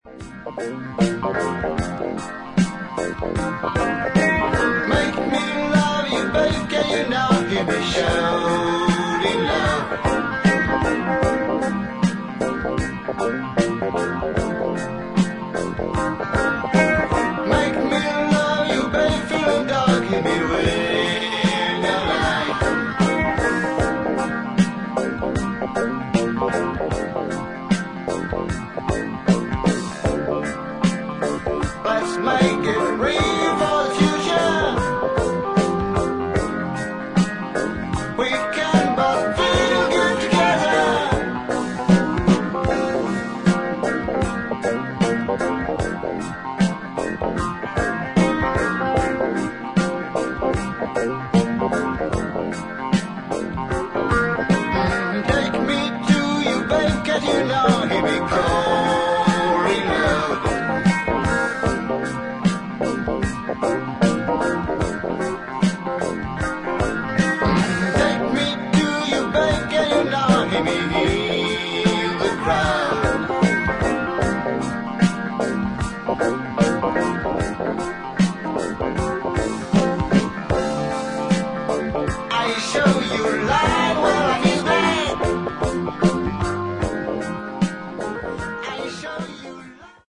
各プレイヤーのスキルが際立つ、ソウルフルでファンキーな名演を披露しています。